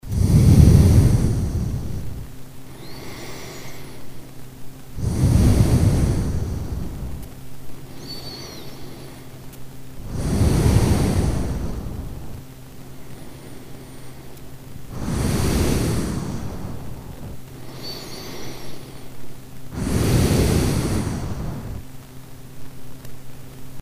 Here's an mp3 I made a couple months ago which was a recording of me breathing while asleep. You can hear that these two patterns are pretty similar:
asleep-breathing.mp3